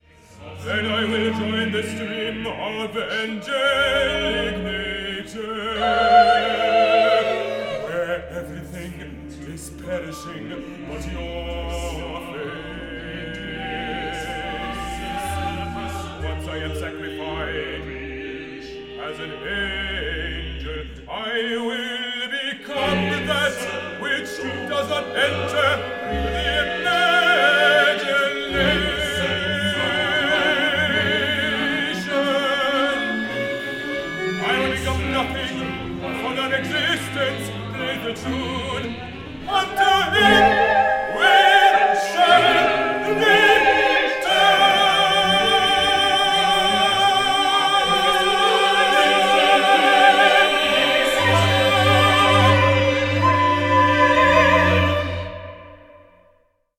Recorded in 2012 at MIAM Studios, Istanbul, Turkey